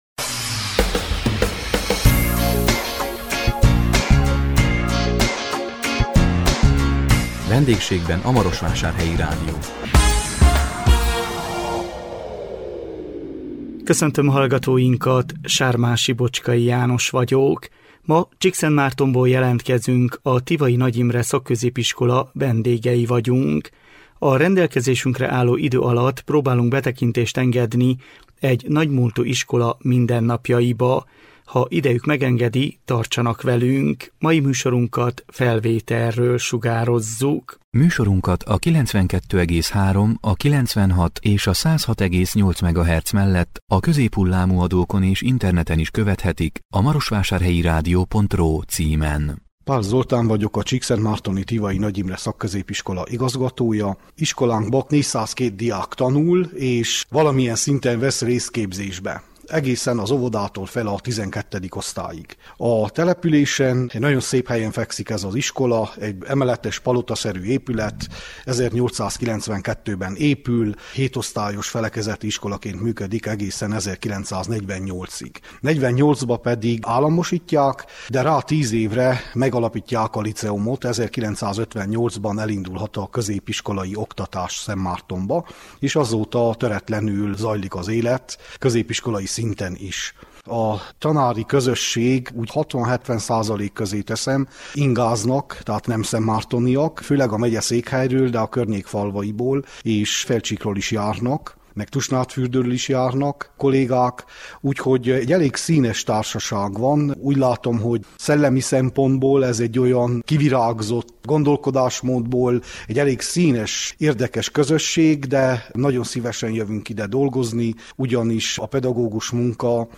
A 2023 január 19-én közvetített VENDÉGSÉGBEN A MAROSVÁSÁRHELYI RÁDIÓ című műsorunkkal Csíkszentmártonból jelentkeztünk, a Tivai Nagy Imre Szakközépiskola vendégei voltunk. A rendelkezésünkre álló idő alatt próbáltunk betekintést engedni egy nagy múltú iskola mindennapjaiba.